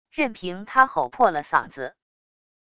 任凭他吼破了嗓子wav音频生成系统WAV Audio Player